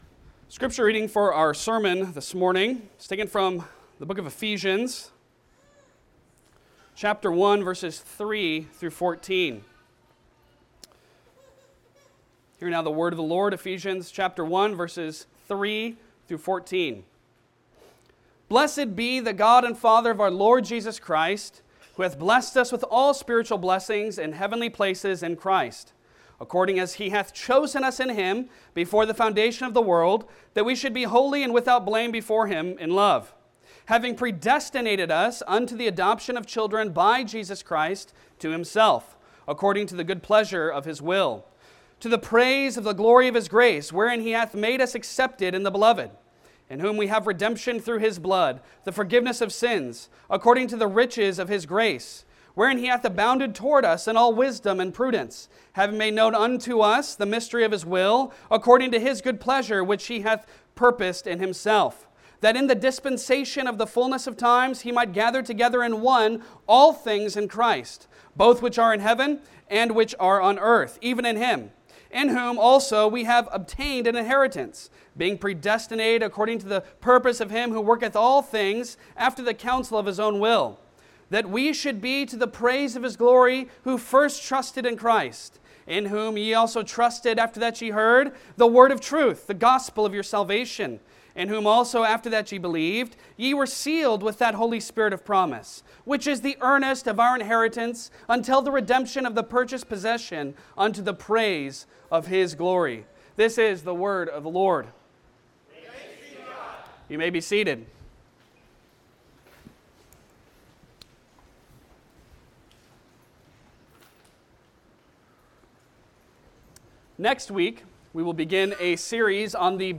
Passage: Ephesians 1:3-14 Service Type: Sunday Sermon Download Files Bulletin Topics